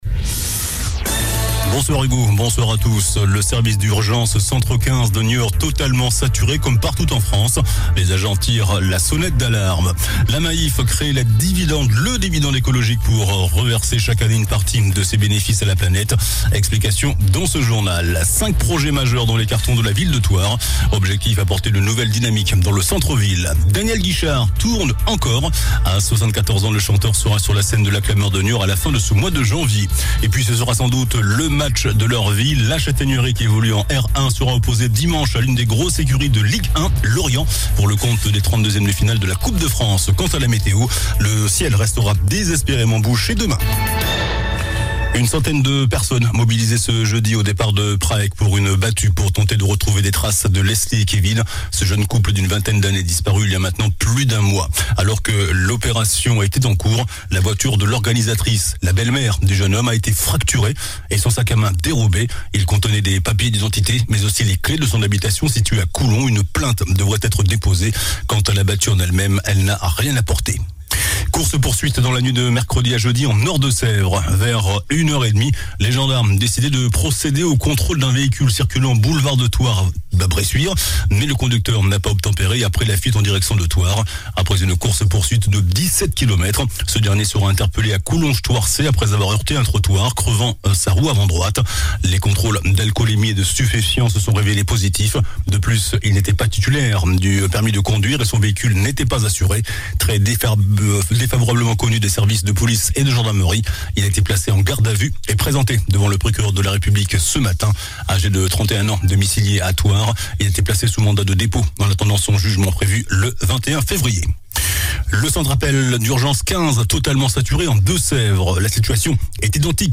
JOURNAL DU JEUDI 05 JANVIER ( SOIR )